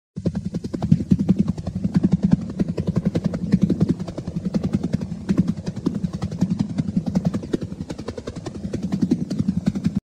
10. Звук бега Флэша
flash-super-skorost.mp3